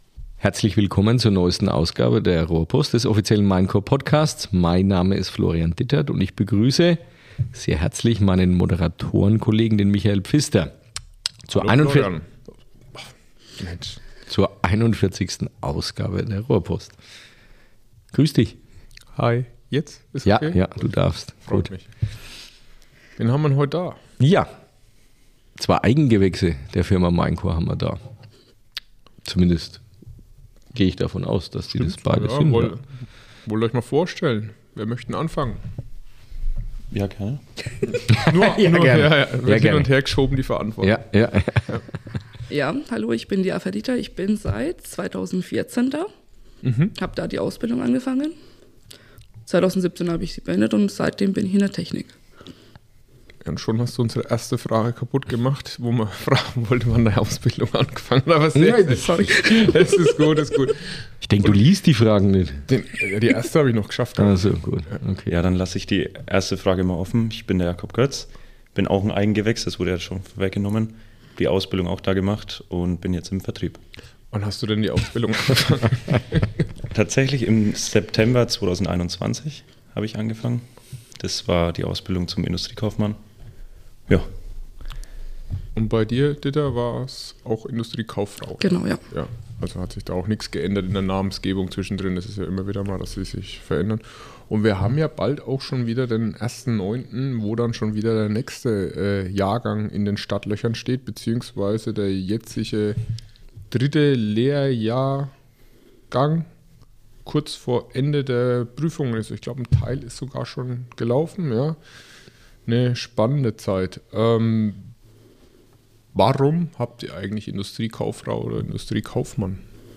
Wir sprechen mit ihnen über ihre Anfänge, Herausforderungen und Erfolge, die sie auf diesem Weg begleitet haben. Freut euch auf persönliche Einblicke, spannende Erfahrungen und ehrliche Antworten auf die Frage: Wie ist es wirklich, bei MAINCOR zu arbeiten – und bleibt man gern?